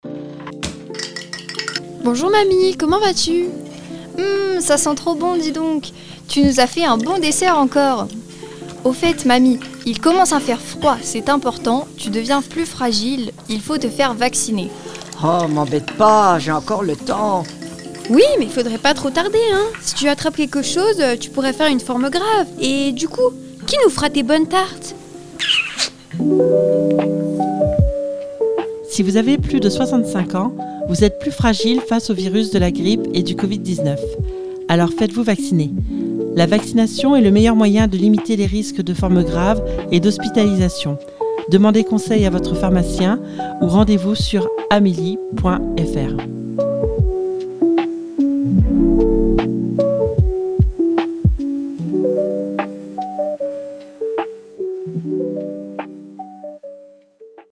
Les productions radiophoniques des participantes !
Un atelier très intergénérationnel !
Message